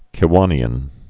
(kĭ-wänē-ən)